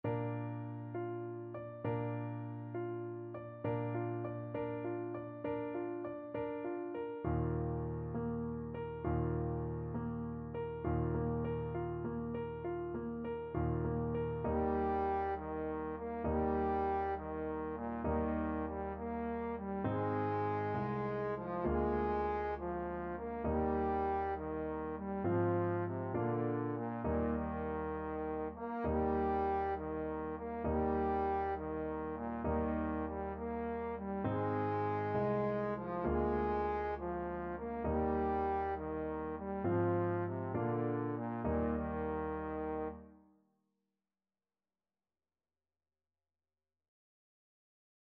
Trombone
Eb major (Sounding Pitch) (View more Eb major Music for Trombone )
6/8 (View more 6/8 Music)
Allegretto
Bb3-Bb4
Classical (View more Classical Trombone Music)